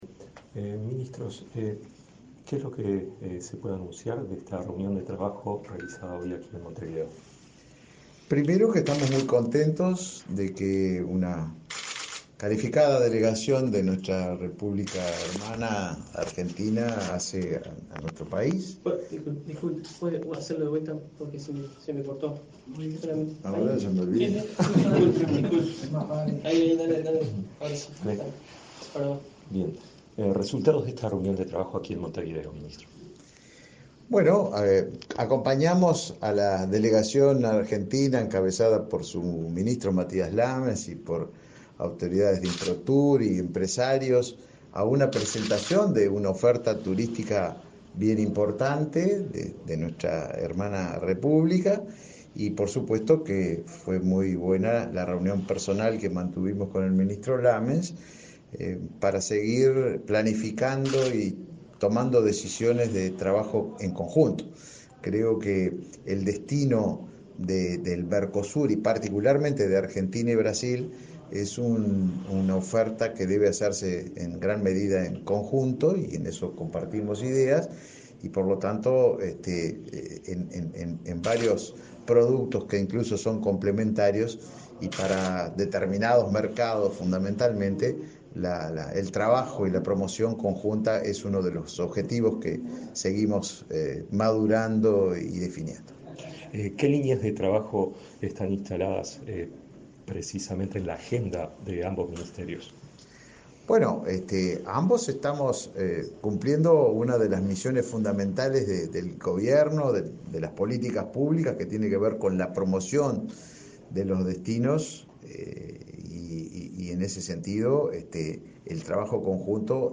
Declaraciones del ministro de Turismo, Tabaré Viera, y su par de Argentina, Matías Lammens
Viera prensa.mp3